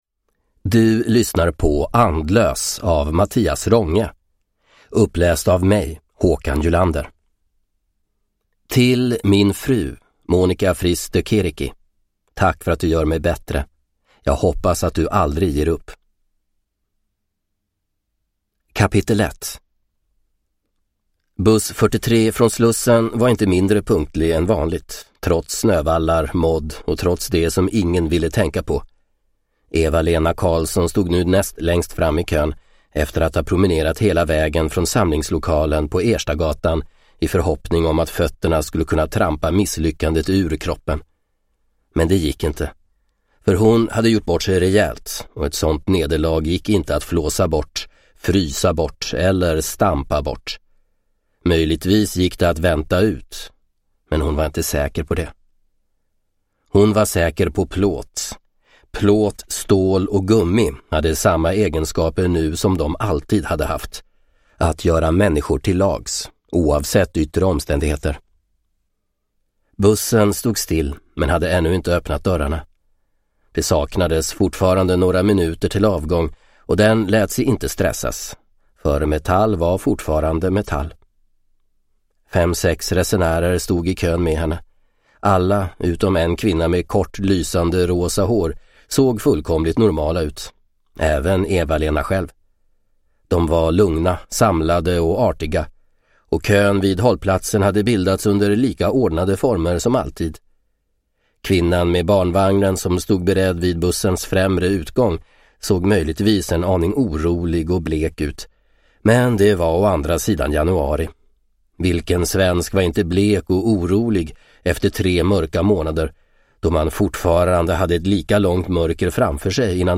Andlös – Ljudbok – Laddas ner